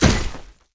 drop_2.wav